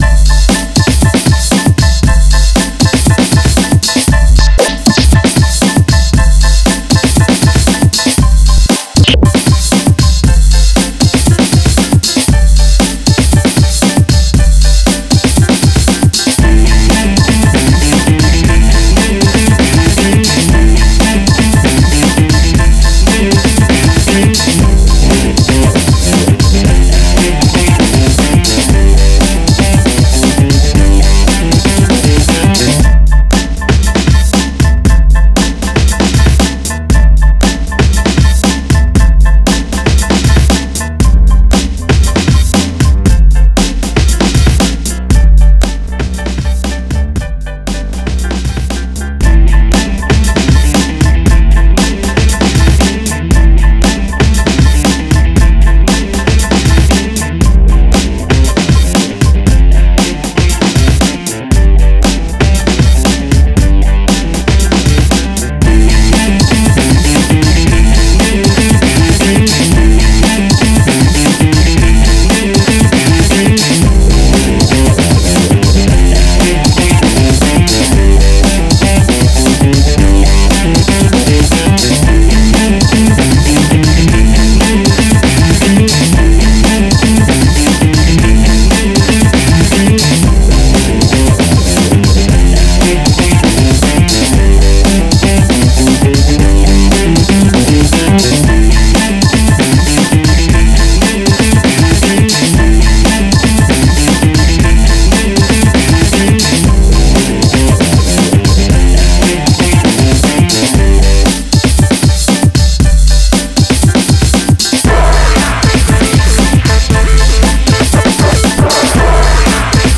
• Жанр: Альтернативная